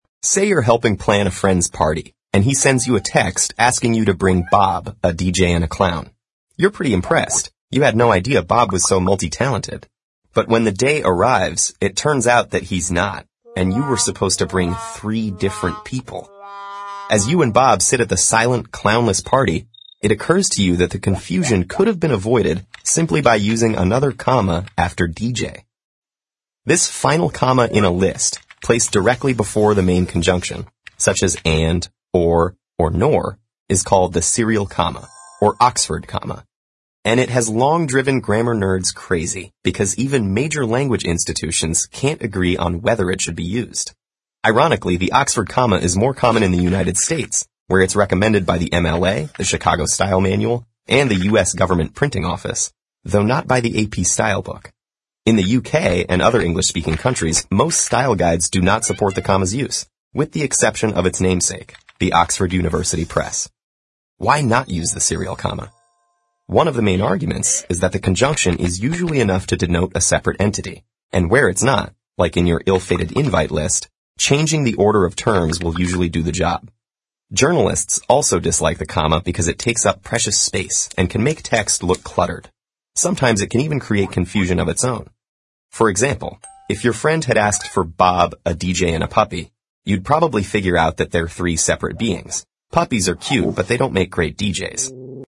TED演讲:语法的分界线 牛津逗号(1) 听力文件下载—在线英语听力室